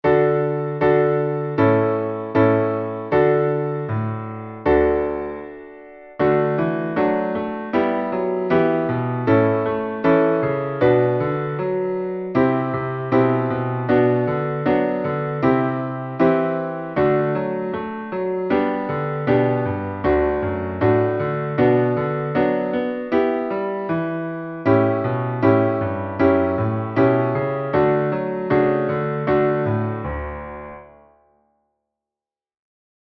Beispiele Klavier
Null_und_Eins___schnell___Klavier.mp3